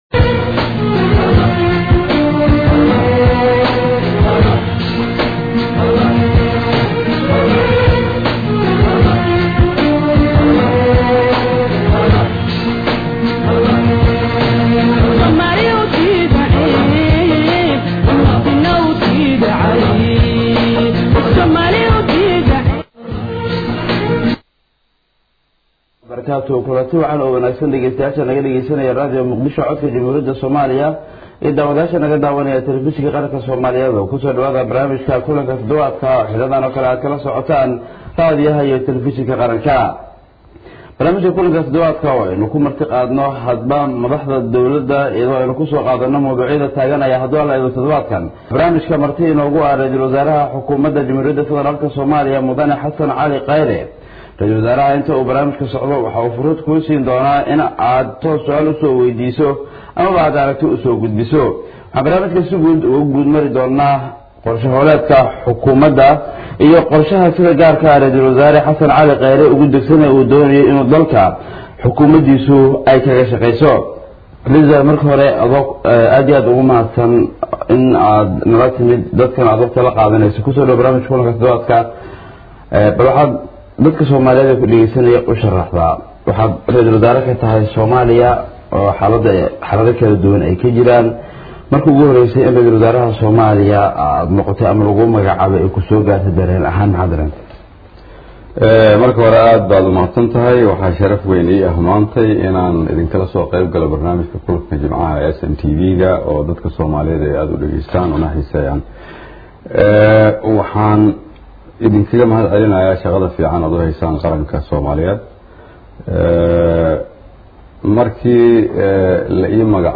Dhageyso: Barnaamijka kulanka todobaadka oo uu marti ku ahaa R/wasaare Kheyrre